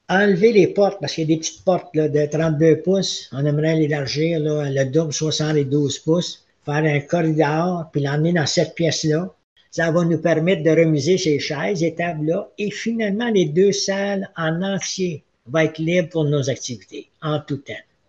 Robert Bergeron, maire de Kazabazua, se réjouit que les travaux soient effectués par un entrepreneur local :